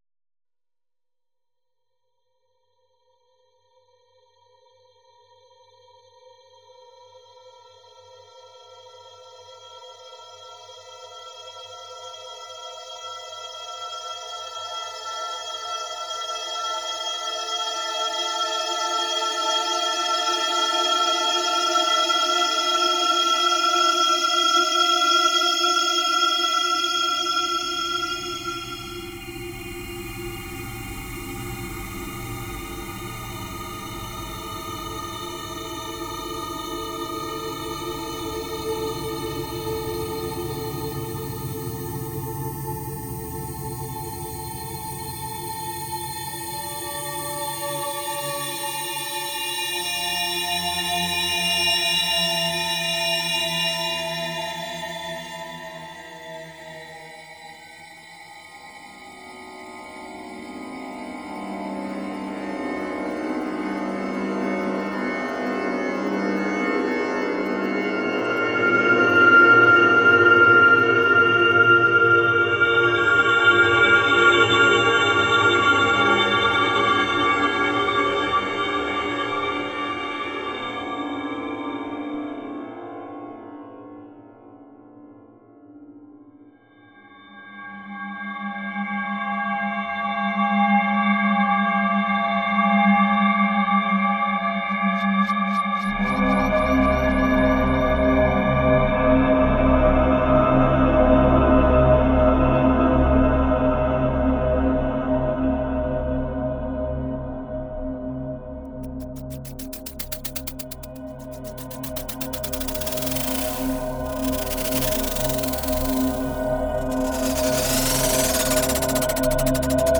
Agent-Based Graphic Sound Synthesis and Acousmatic Composition